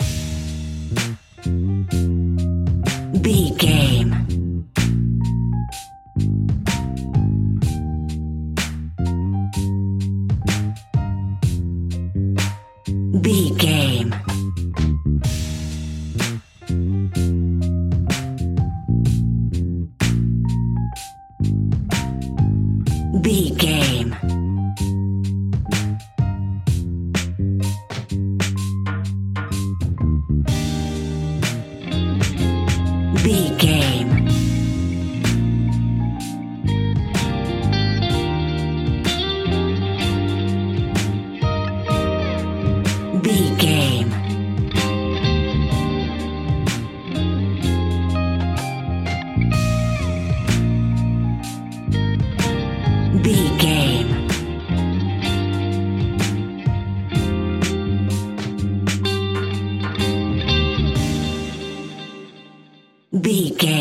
Ionian/Major
D♯
chilled
laid back
Lounge
sparse
new age
chilled electronica
ambient
atmospheric
instrumentals